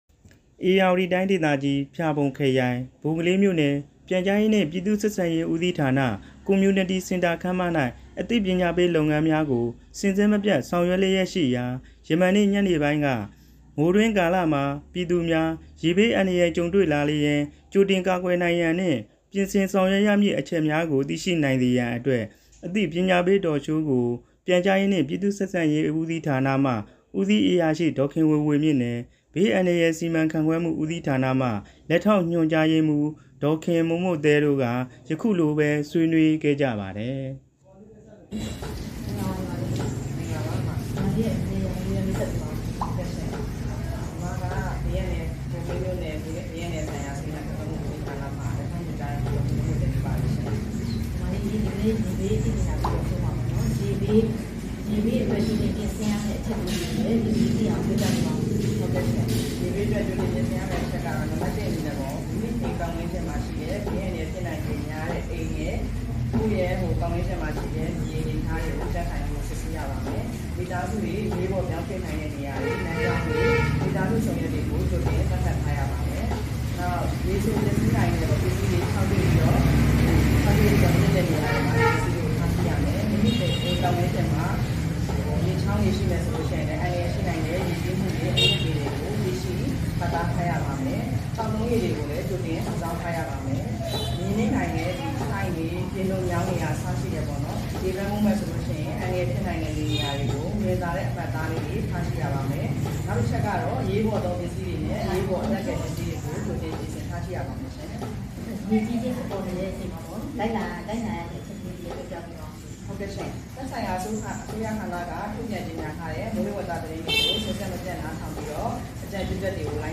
ဘိုကလေးမြို့နယ် Community Centre ခန်းမတွင် ရေဘေးအန္တရာယ် အသိပညာပေး Talk Show ကျင်းပ